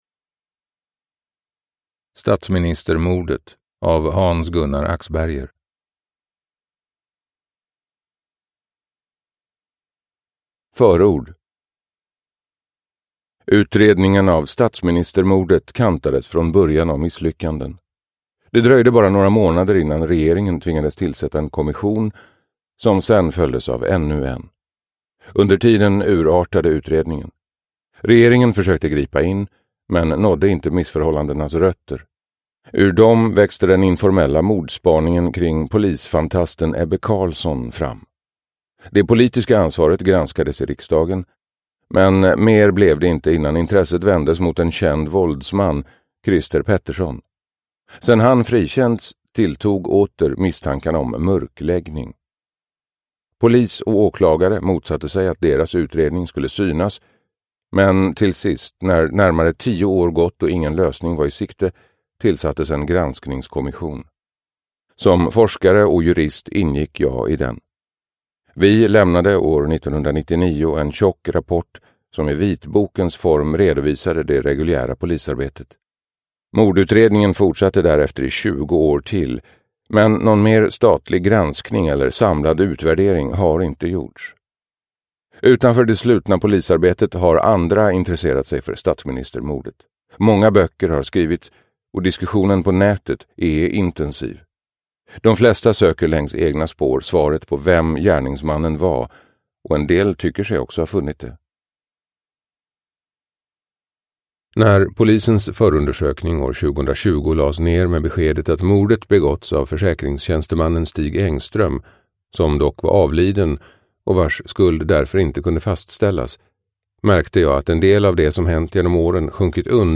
Statsministermordet – Ljudbok – Laddas ner